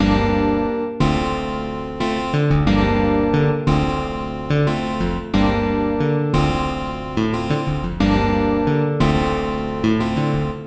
描述：嘻哈吉他
Tag: 90 bpm Hip Hop Loops Guitar Acoustic Loops 1.79 MB wav Key : Unknown